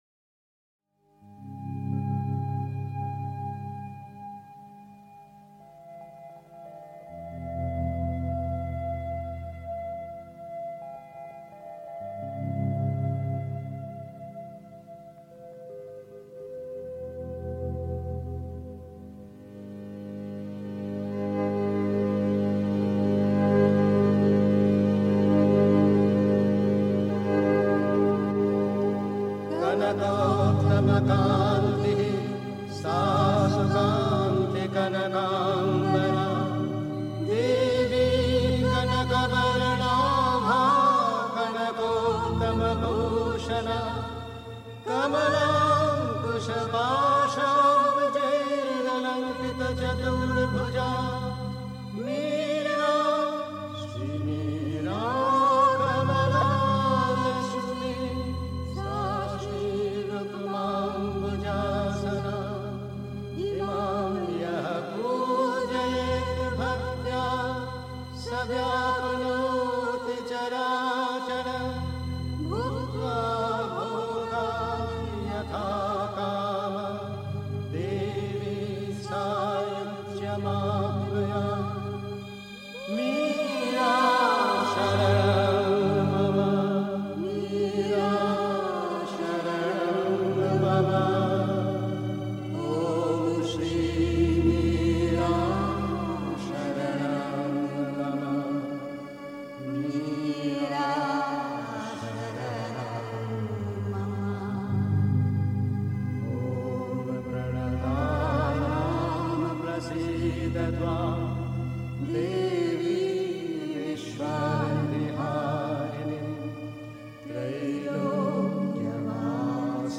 Pondicherry. 2. Das Gesetz des spirituellen Lebens (Sri Aurobindo, CWSA Vol 29, p. 56) 3. Zwölf Minuten Stille.